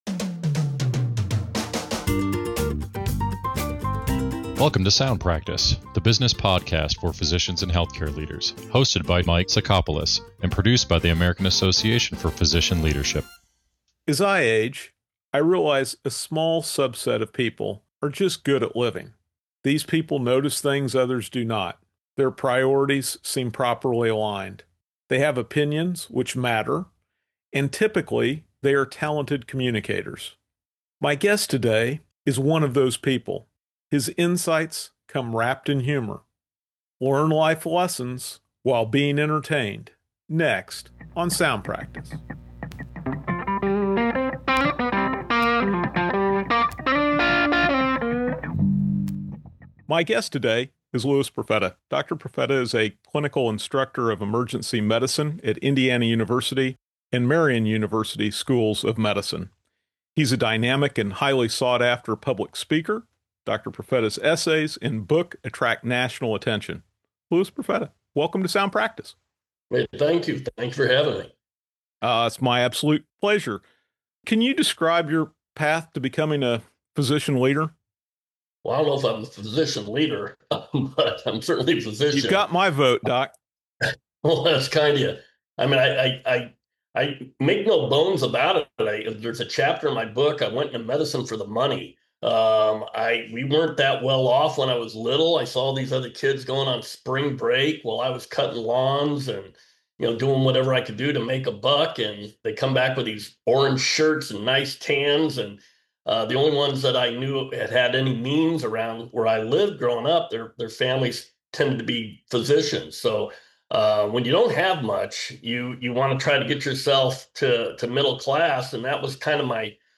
The conversation explores the unique position of the ER as society's great equalizer, where everyone from premature babies to Fortune 500 CEOs receive care under one roof.